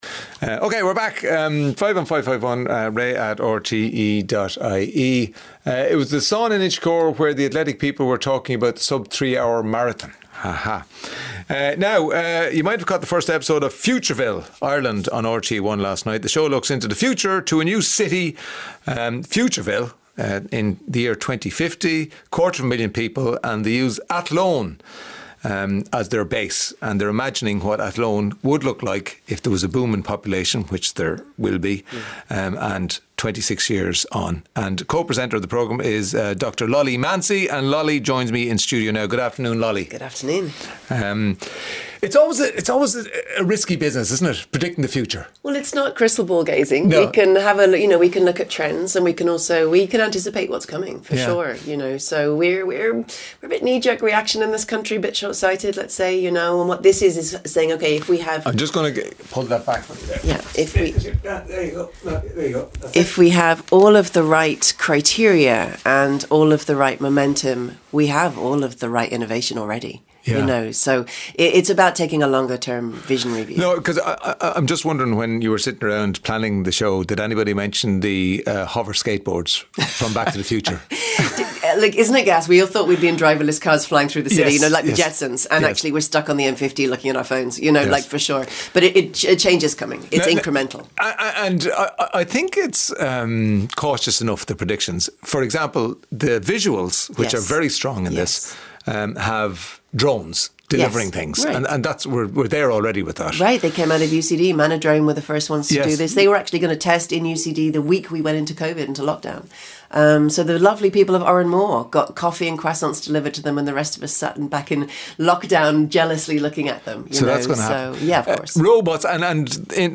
Highlights from the daily radio show with Ray D'Arcy. Featuring listeners' stories and interviews with authors, musicians, comedians and celebrities.